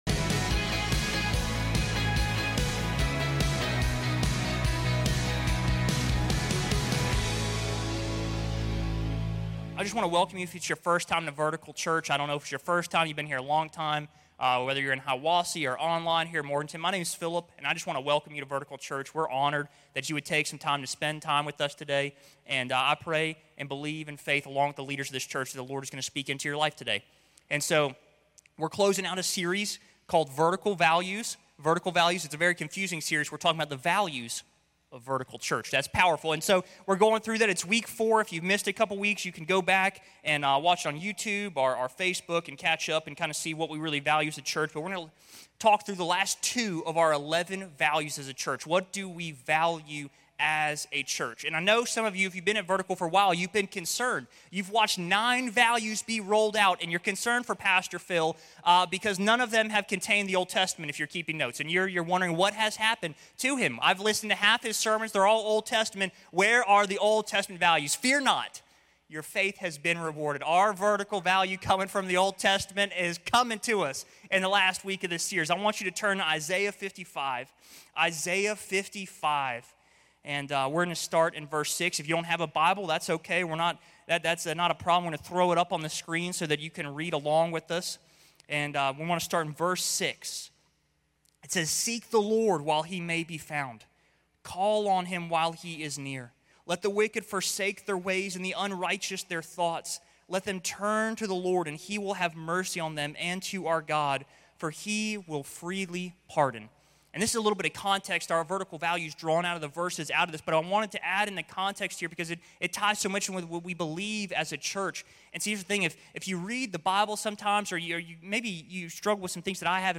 This message is the second installment of our new series, "Vertical Values."